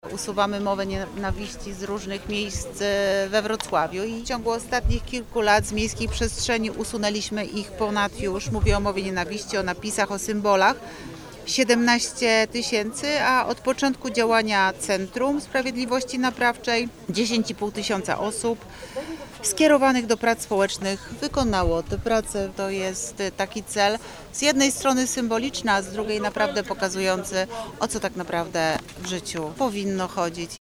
W ostatnich latach z wrocławskiej przestrzeni publicznej usunięto 17 tys. słów, symboli i znaków nienawiści, zaznacza Renata Granowska, wiceprezydent Wrocławia.